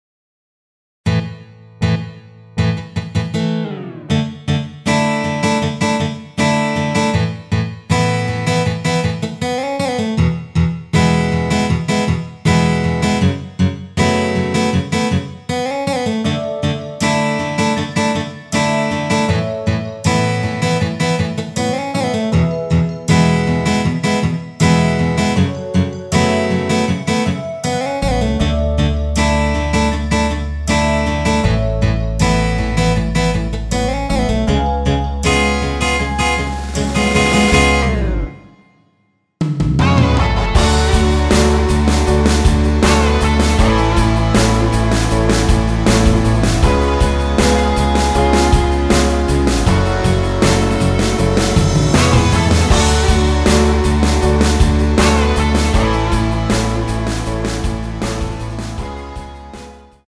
F#m
앞부분30초, 뒷부분30초씩 편집해서 올려 드리고 있습니다.
중간에 음이 끈어지고 다시 나오는 이유는